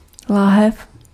Ääntäminen
Synonyymit (armeijaslangi) čutora flaška Ääntäminen : IPA: [ˈlaːɦɛf] Haettu sana löytyi näillä lähdekielillä: tšekki Käännös Ääninäyte 1. bottiglia {f} 2. biberon {m} Suku: f .